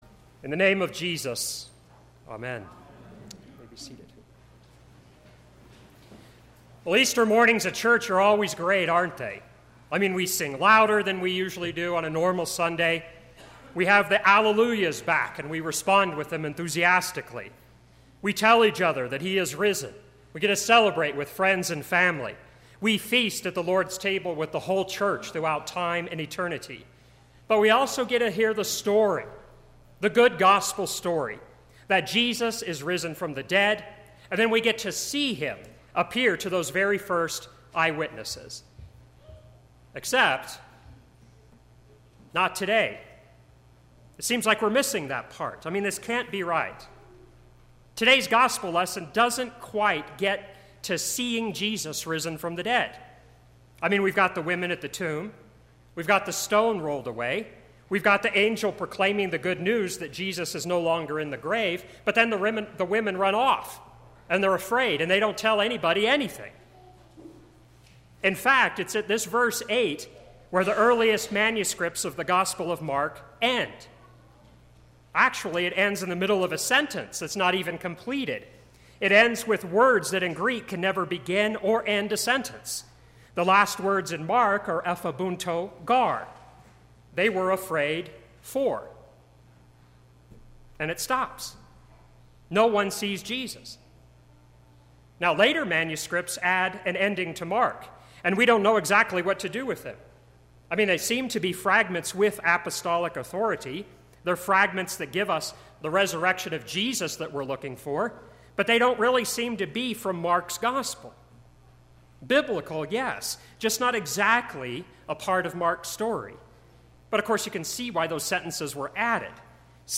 Easter Morning Service 10AM
Easter Sermon – 4/16/2017